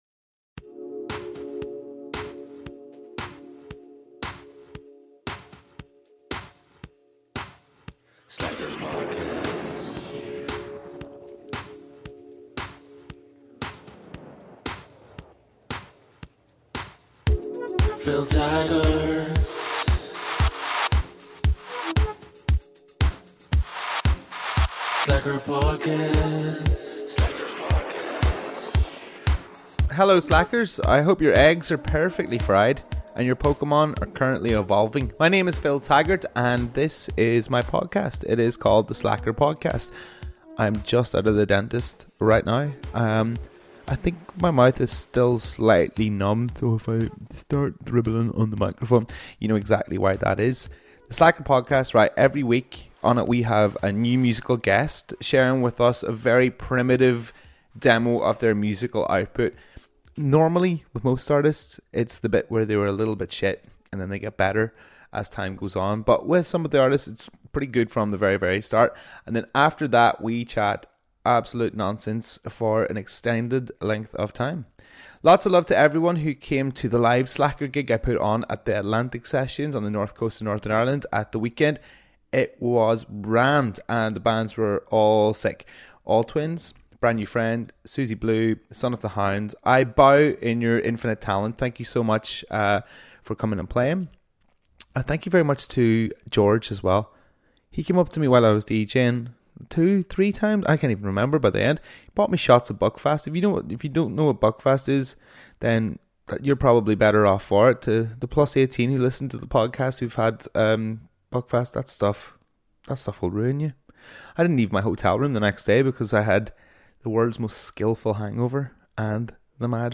I'm a music broadcaster and i started this podcast to have long form interviews with artists and to hear their very early demo's.